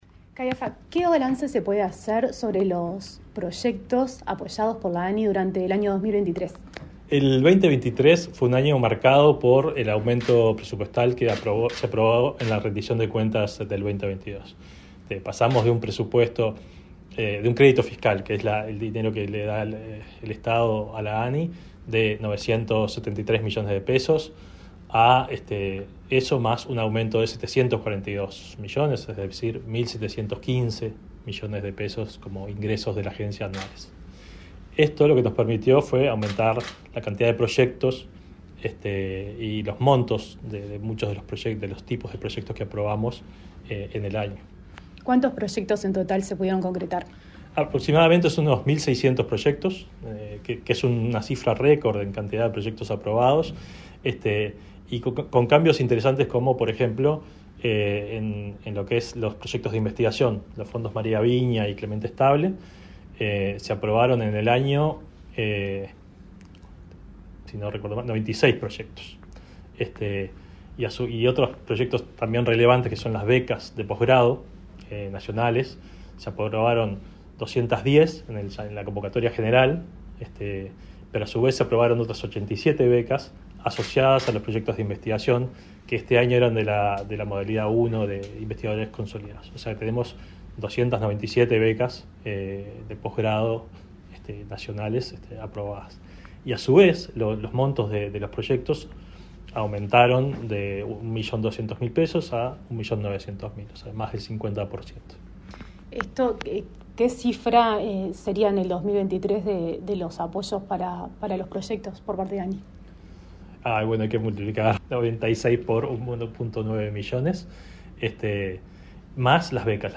Entrevista al presidente de la ANNI, Flavio Caiafa